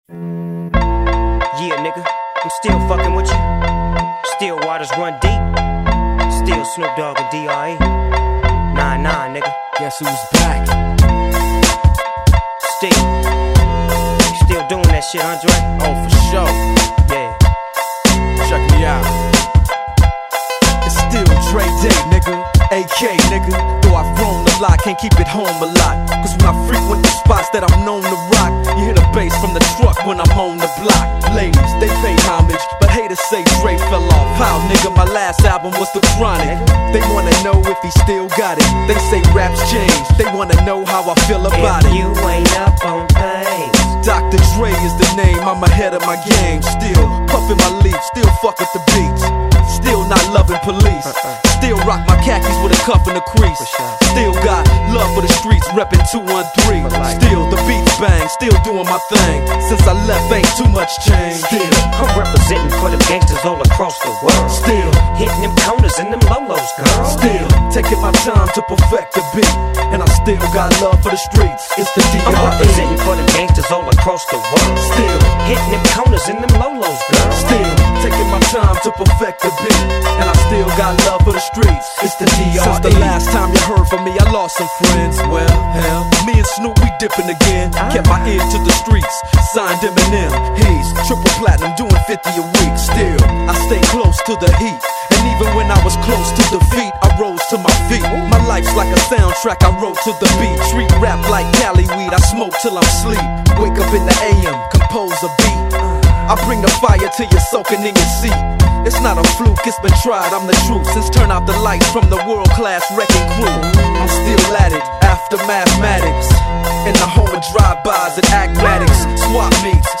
Назад в (rap)...
Файл в обменнике2 Myзыкa->Рэп и RnВ